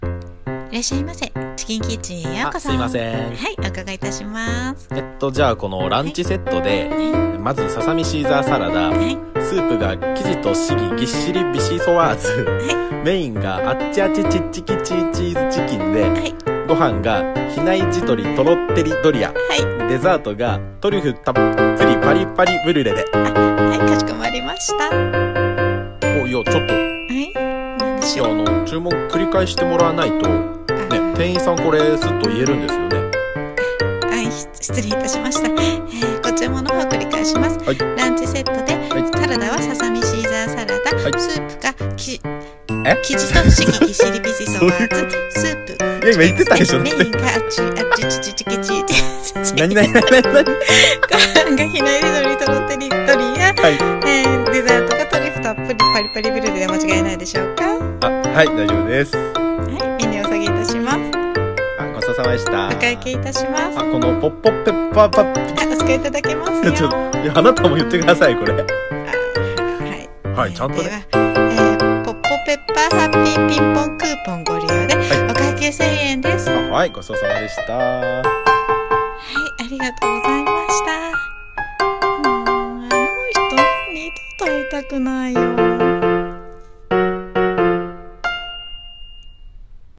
レストラン声劇「チキンキッチン」